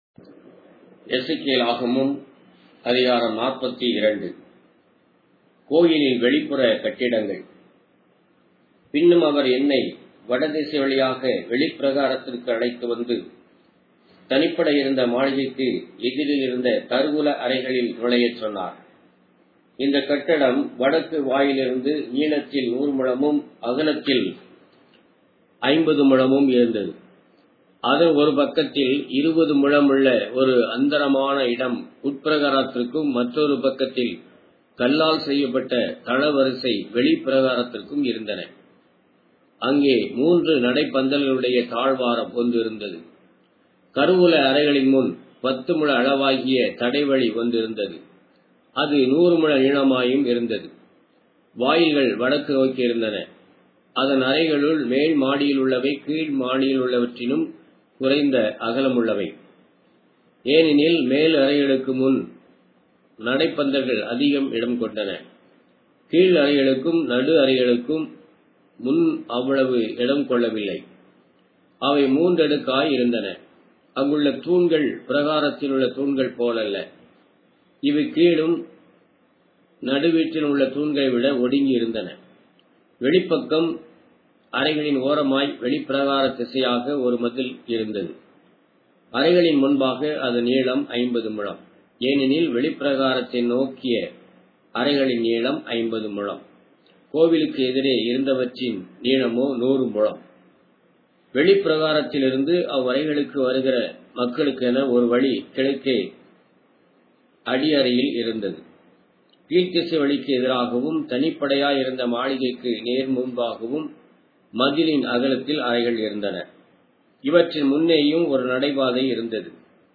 Tamil Audio Bible - Ezekiel 3 in Rcta bible version